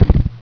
helicopter-skid.wav